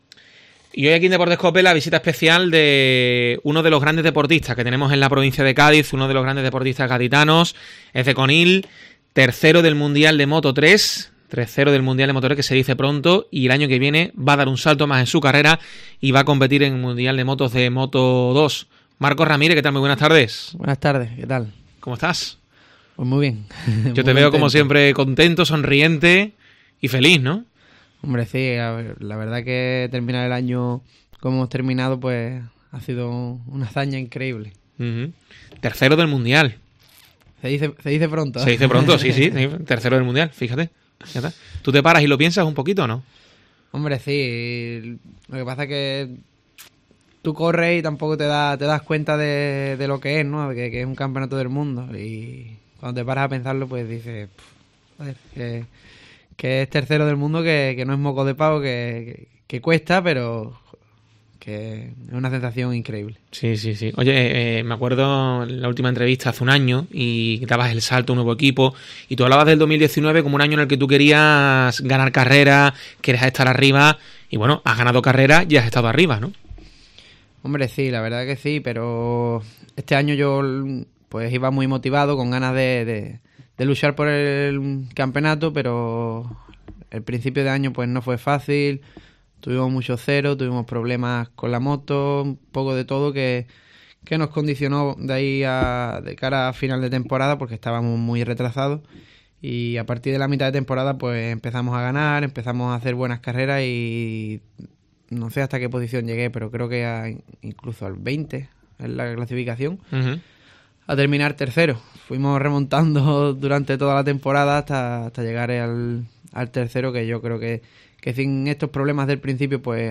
Entrevista al piloto conileño de Moto 2 Marcos Ramírez